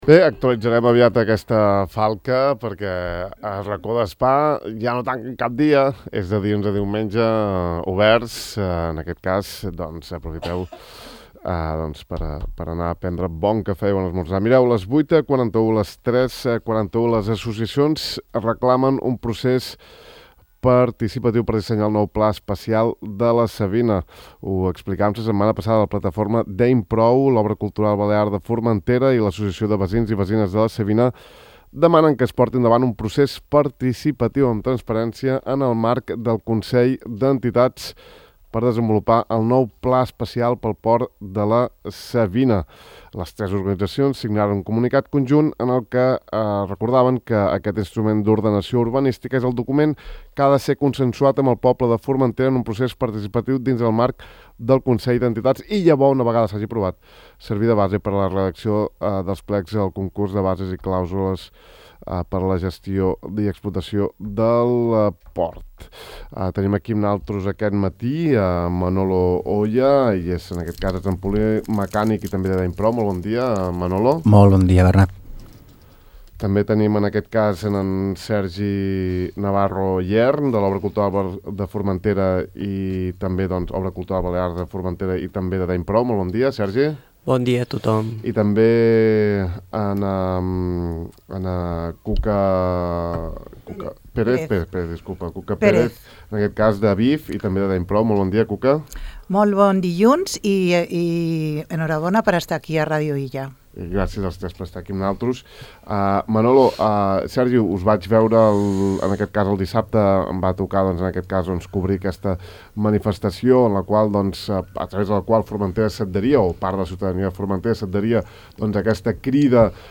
Avui, al De far a far, hem volgut donar veu als membres d’aquestes entitats.